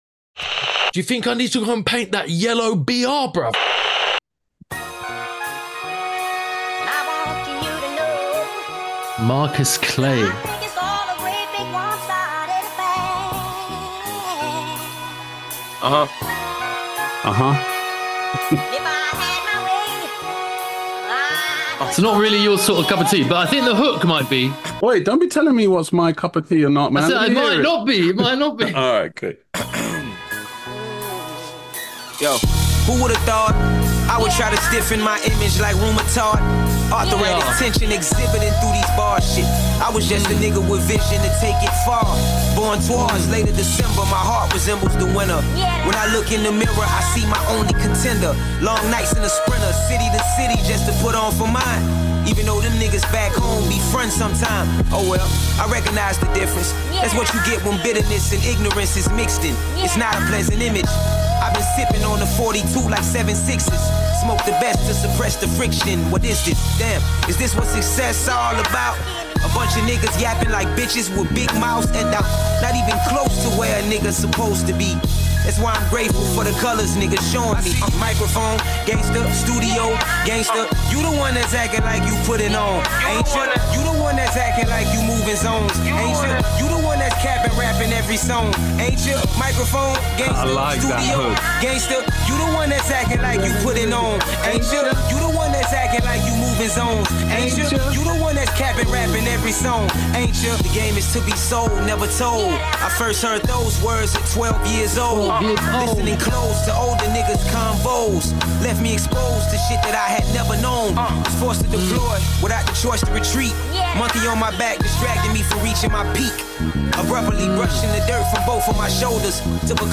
This week we answer listeners questions, listen to music and Critique your Graff.... Music too!